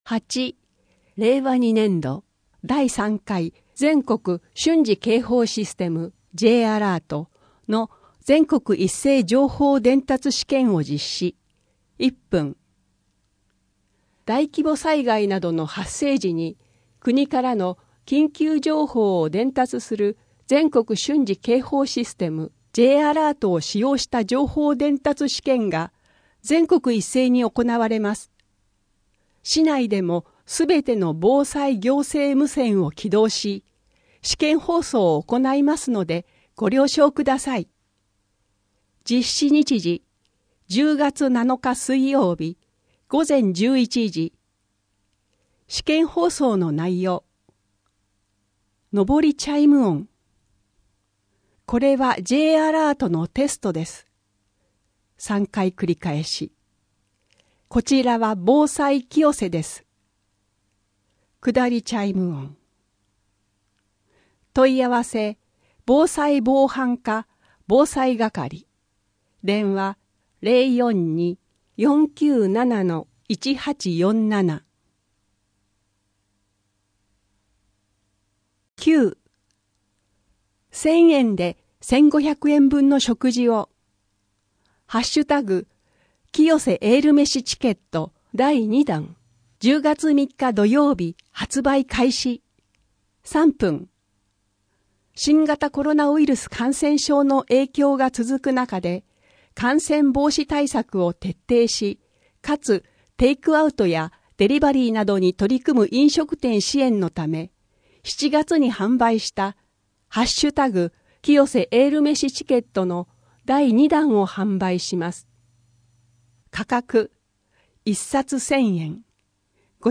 下水道」～下水道に油を流さないで～ 福祉法律専門相談 東京都最低賃金 119番通報は落ち着いて正しく 赤い羽根共同募金運動 寄付・寄贈 夕やけこやけ 各館のイベント情報・お知らせ 郷土博物館からのお知らせ 図書館からのお知らせ 健康増進室・初回トレーニング講習再開 10月の子育て関連事業 10月の休日診療 声の広報 この声の広報は清瀬市公共刊行物音訳機関が制作しています。